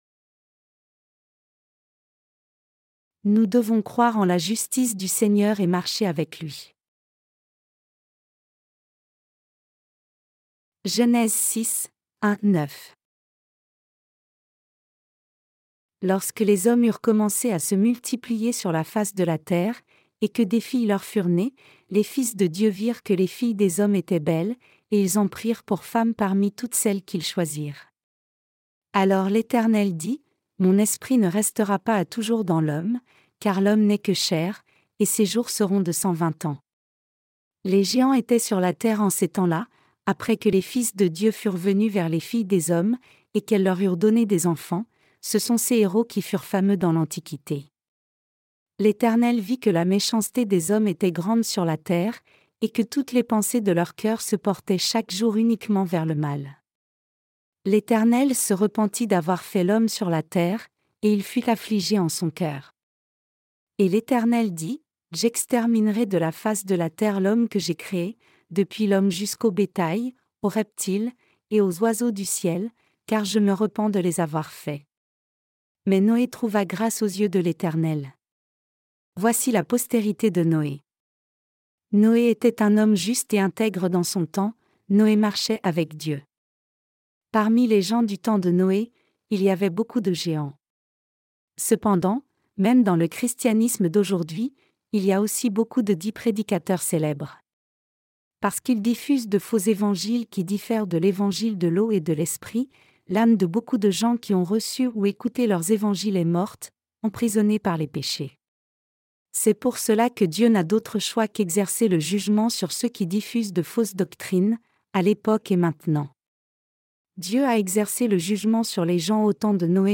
Sermons sur la Genèse (V) - LA DIFFERENCE ENTRE LA FOI D’ABEL ET LA FOI DE CAÏN 14.